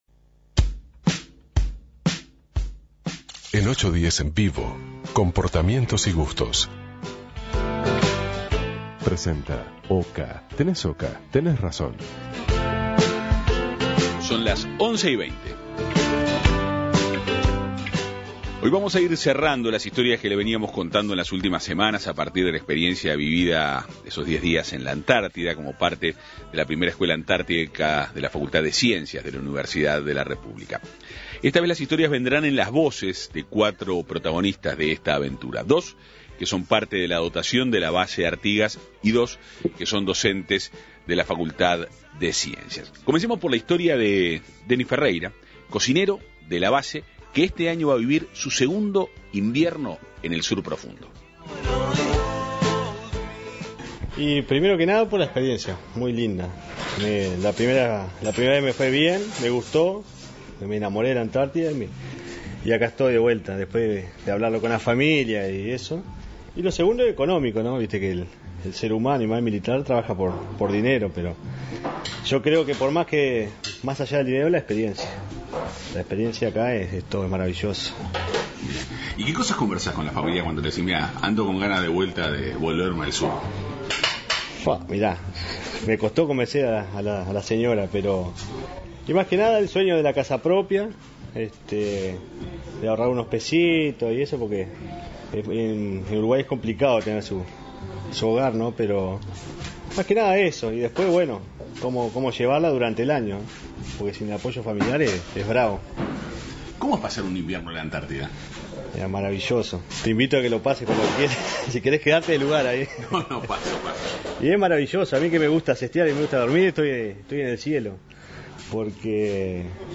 Hoy en 810 Vivo Avances, tendencias y actualidad, hacemos un cierre de las historias que compartimos las últimas semanas sobre la experiencia en el continente antártico a partir de la primera Escuela Antártica de Verano de la Facultad de Ciencias. Para ello conversamos con cuatro protagonistas de esta aventura.